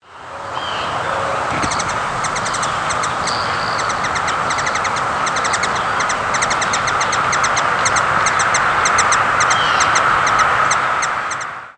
Flight call description A soft, humming "tchif", often doubled or repeated in a series.
Immature male perched with Blue Jay calling in the background.